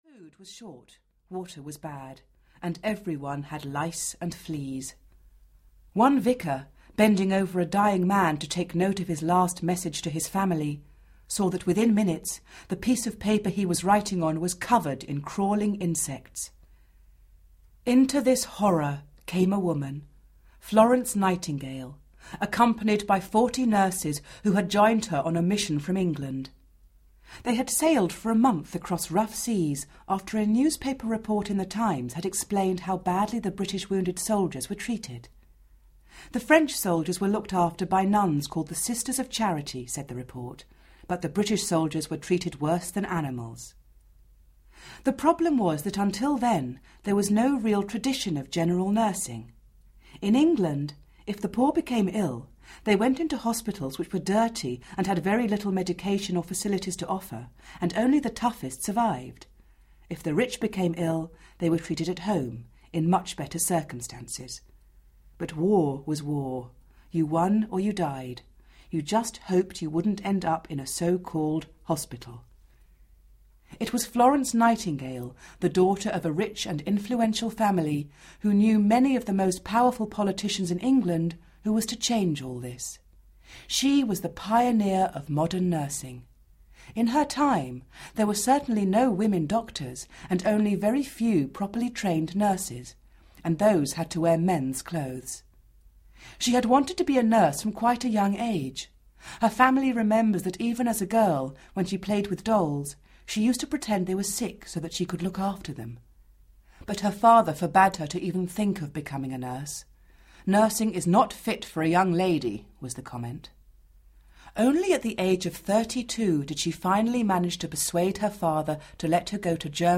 Famous People in History Audiobook
2.5 Hrs. – Unabridged